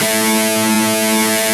Index of /90_sSampleCDs/Roland L-CDX-01/GTR_Distorted 1/GTR_Power Chords